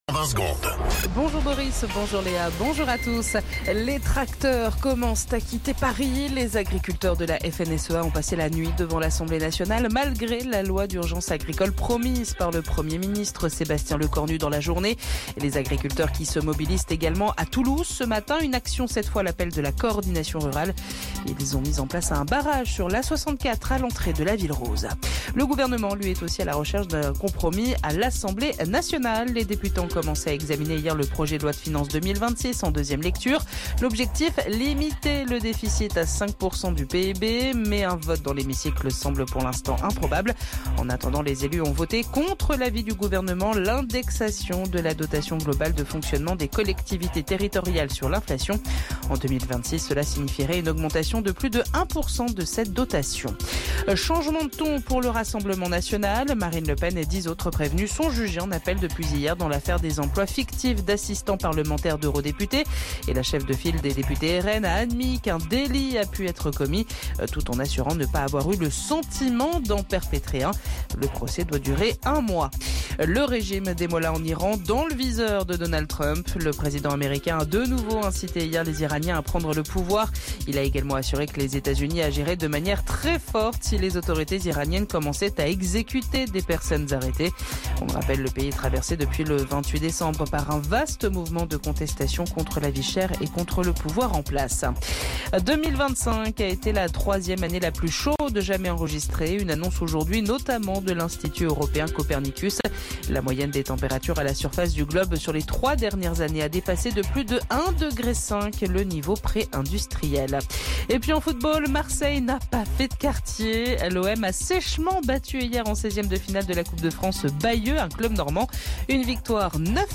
Flash Info National 14 Janvier 2026 Du 14/01/2026 à 07h10 .